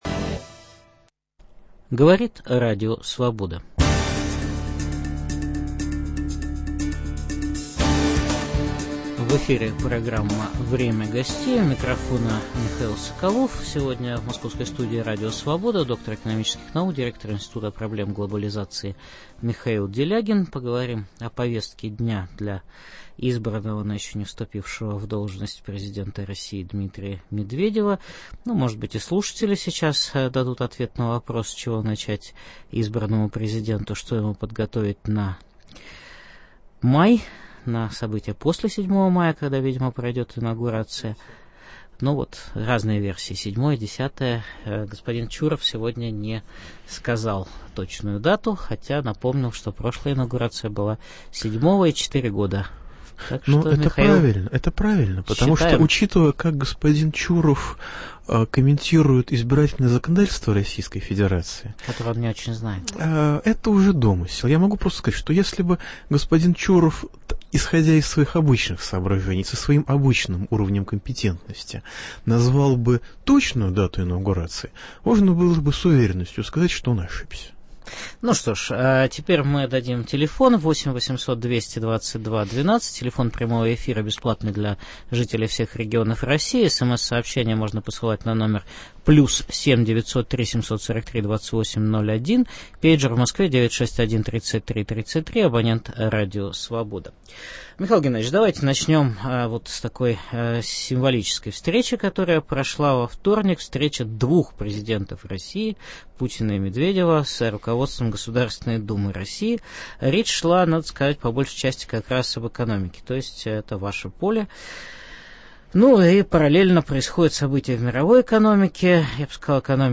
Гость – доктор экономических наук Михаил Делягин.